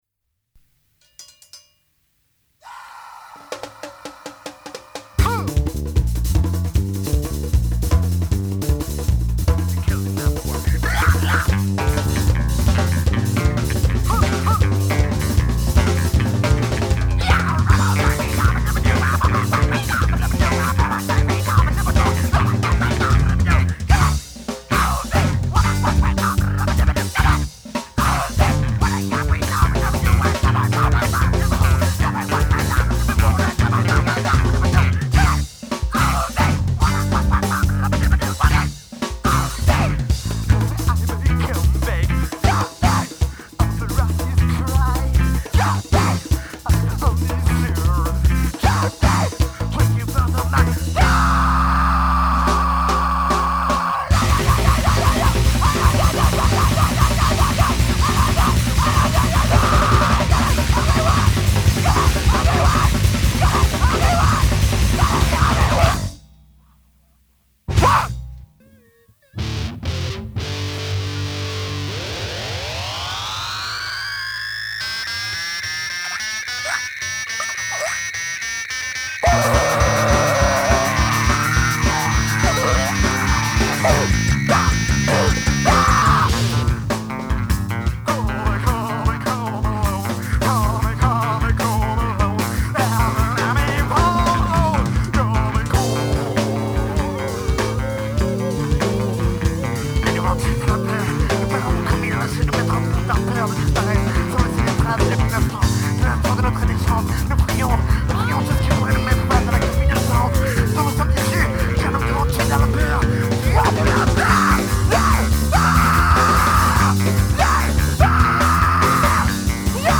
bass
microphone
drums